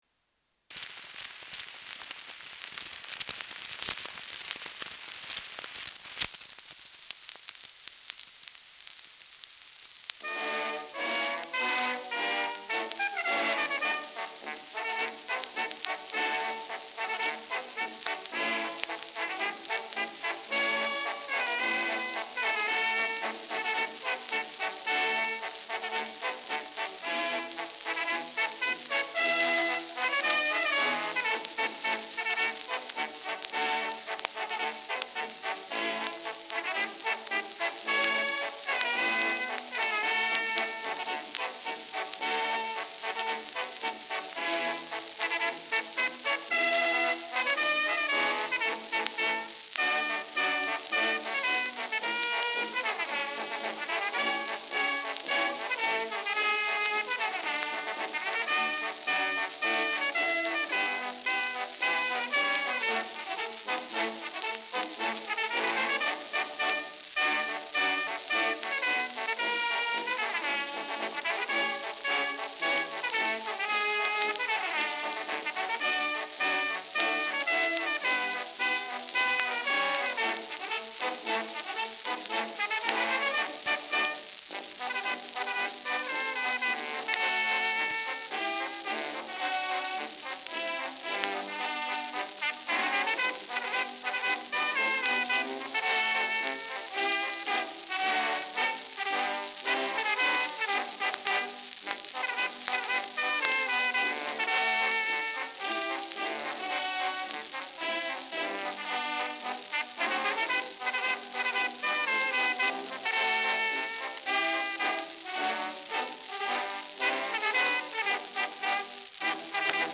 Schellackplatte
stärkeres Grundrauschen : dünner Klang